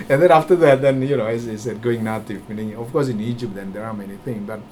S1 = Taiwanese female S2 = Indonesian male Context: S2 is talking about how he eventually learned how adapt to the food when he was living in Cairo.
Intended Words: going native Heard as: good to eat Discussion: The main problem here seems to be the vowel in the first syllable of native , which is [æ] rather than the expected [eɪ]